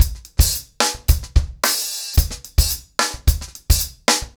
TimeToRun-110BPM.27.wav